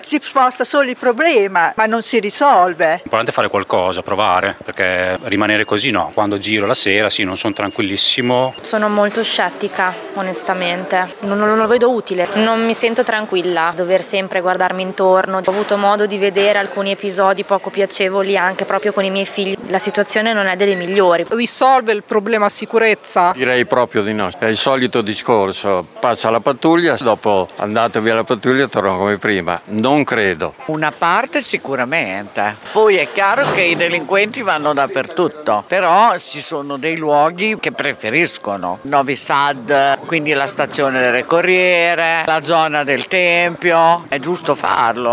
Vox Populi 0
Serviranno a combattere la criminalità? Le opinioni dei modenesi